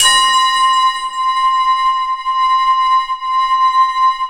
POWERBELL C6.wav